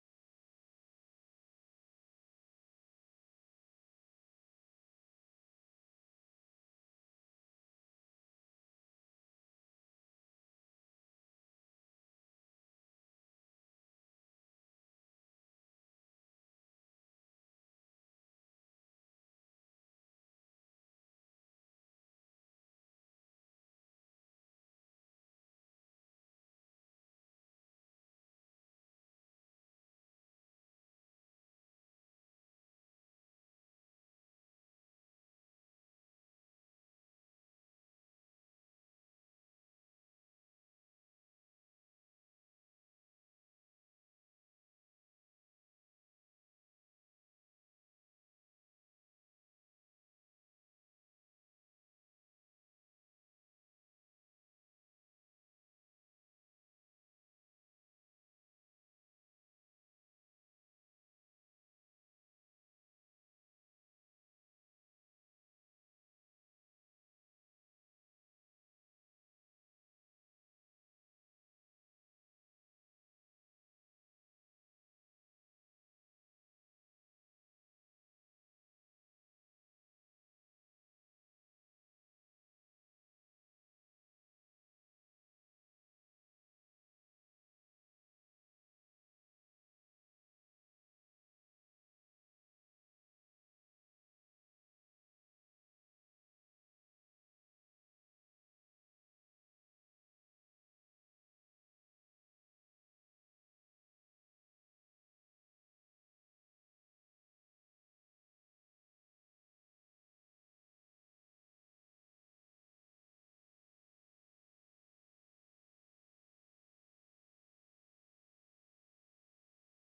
Our talented worship team helps us give praise to the Lord this Sunday morning.